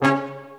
BRASSHTC#4.wav